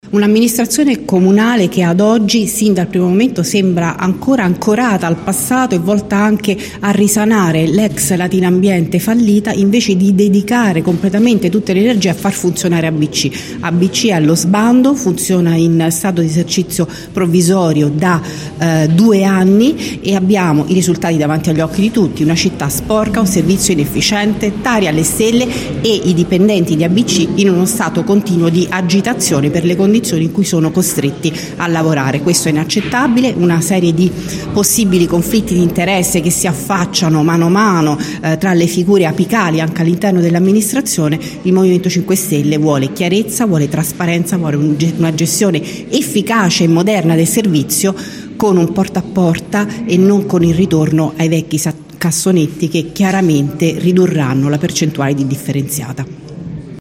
In una conferenza stampa che si è tenuta questa mattina nella sede di via Cattaneo, la capogruppo del movimento 5 stelle Maria Grazia Ciolfi,  quella del Pd Valeria Campagna, il capogruppo di Lbc Dario Bellini e il capogruppo di Latina per 2032 Nazareno Ranaldi hanno affrontato la questione che ha portato da due anni a questa parte a puntuali scontri in consiglio comunale.